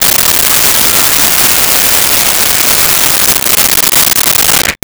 Blender On Grate
Blender on Grate.wav